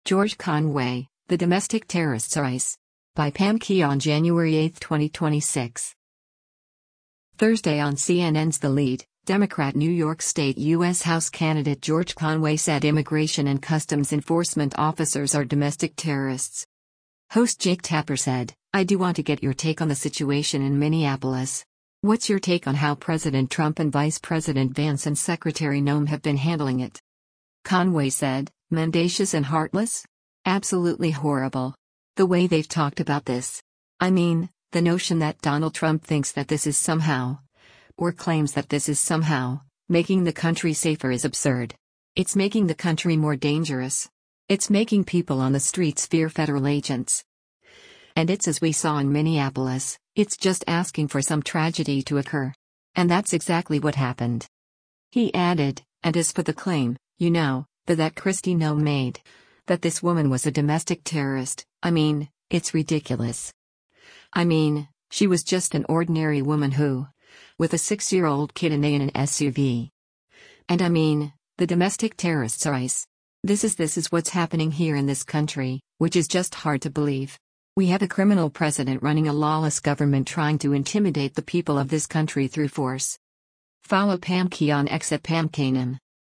Thursday on CNN’s “The Lead,” Democrat New York State U.S. House candidate George Conway said Immigration and Customs Enforcement officers are “domestic terrorists.”